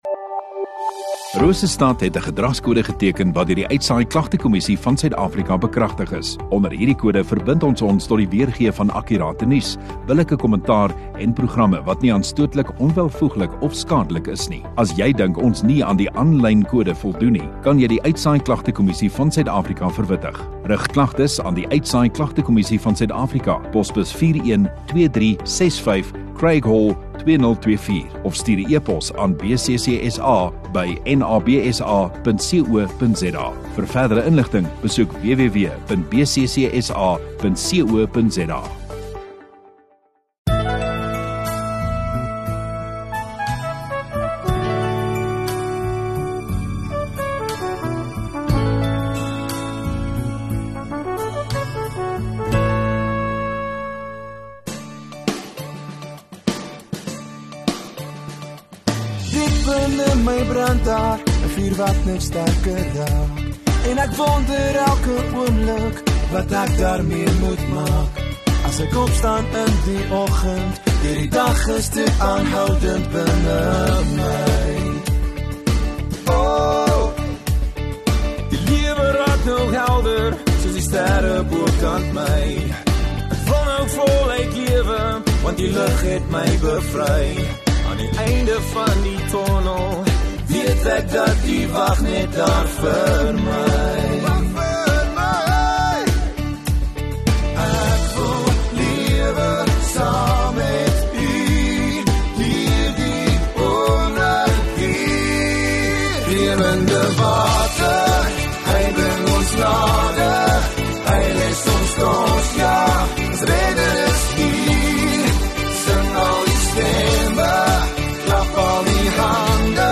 10 May Saterdag Oggenddiens